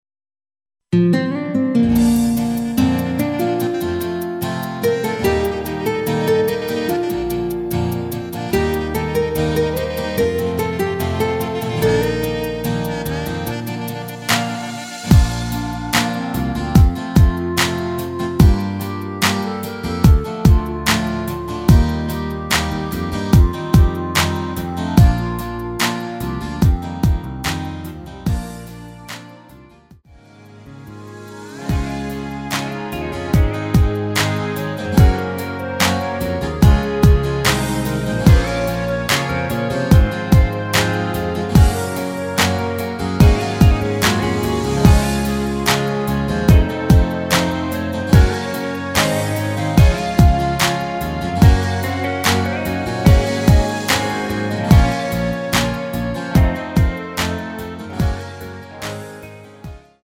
원키에서(-6)내린 멜로디 포함된 MR입니다.
Bb
앞부분30초, 뒷부분30초씩 편집해서 올려 드리고 있습니다.
중간에 음이 끈어지고 다시 나오는 이유는